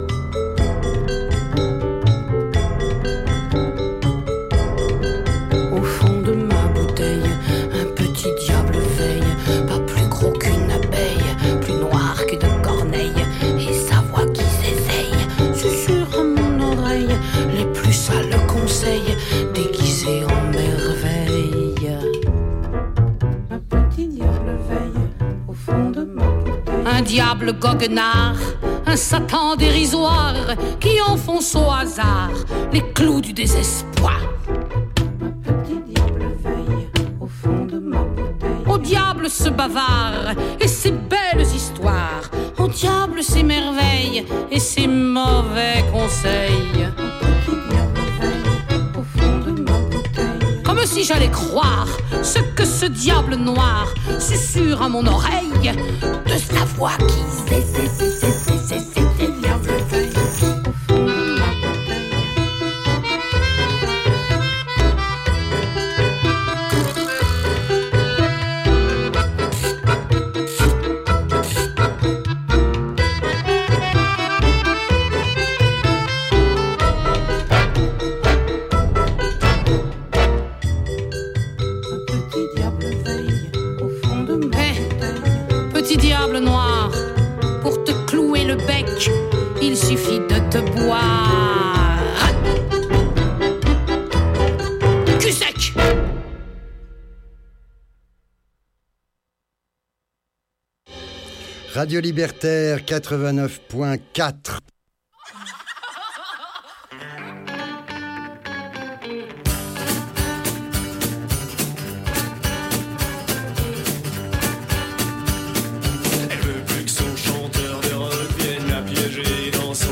L'enregistrement démarre 1 mn avant l'heure prévue du début de l'émission